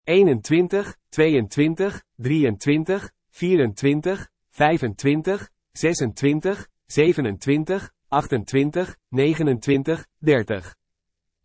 pronunciation from 20 to 30: